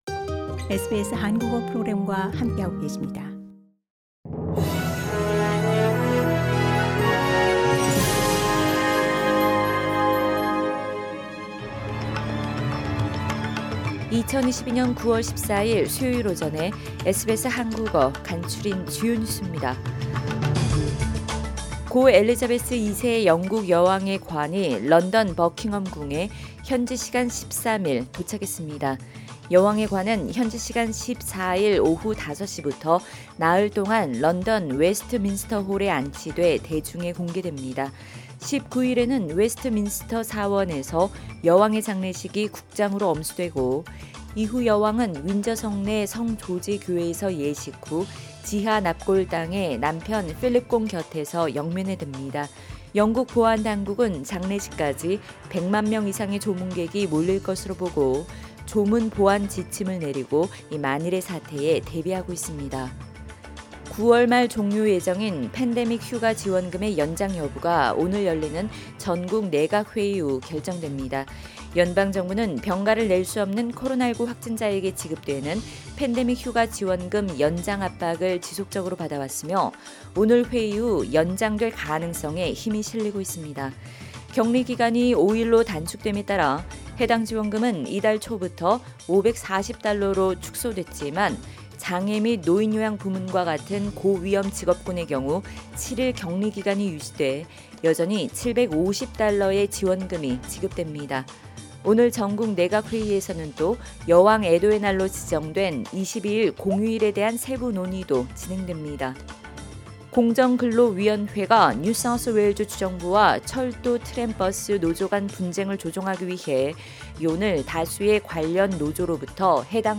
SBS 한국어 아침 뉴스: 2022년 9월 14일 수요일
2022년 9월 14일 수요일 아침 SBS 한국어 간추린 주요 뉴스입니다.